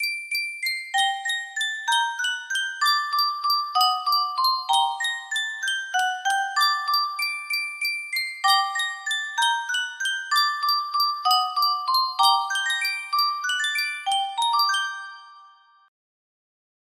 Sankyo Music Box - Franz Liszt La Campanella KXB music box melody
Full range 60